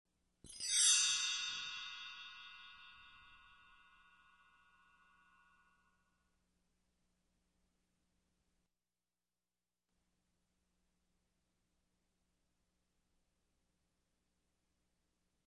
Перезвон колокольчиков — волшебные звуки
Звук с направлением движения — назад
Perezvon_nazad.mp3